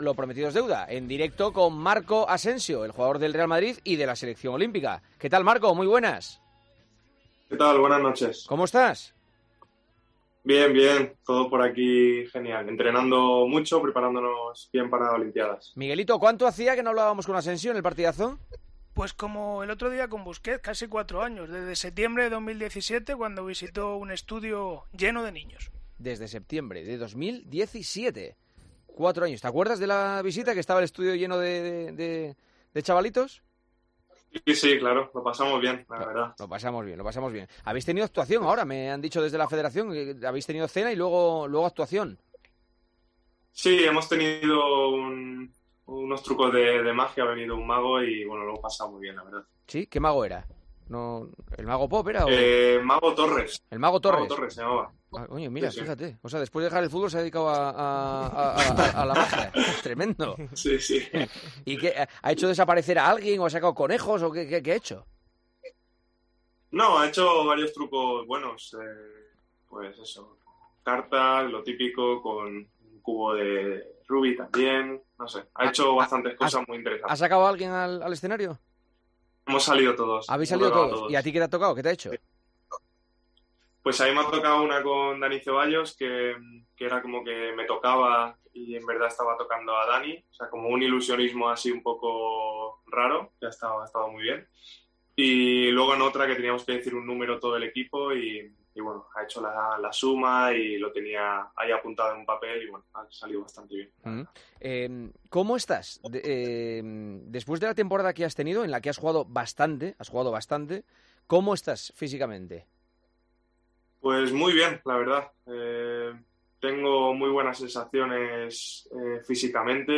AUDIO: El atacante del Real Madrid analizó en El Partidazo de COPE su no convocatoria para la Eurocopa, la próxima temporada con el Real Madrid, los...